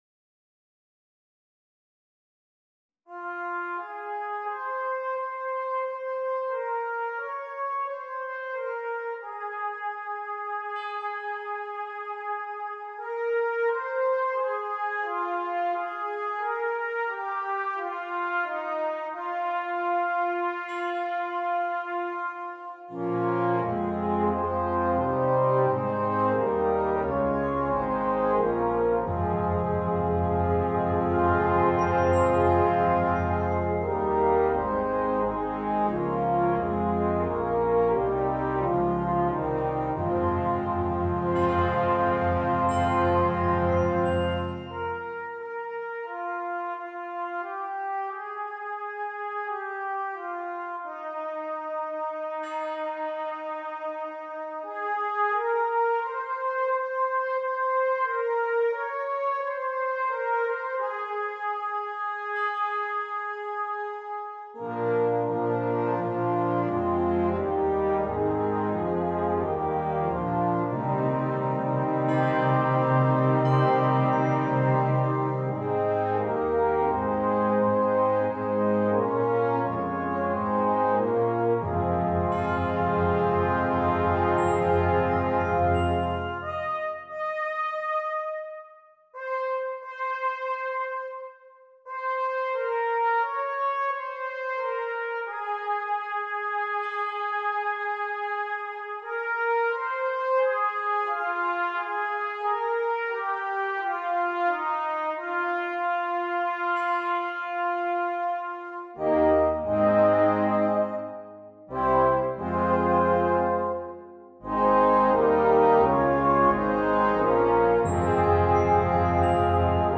Brass Choir (4.2.2.1.1.perc)
Traditional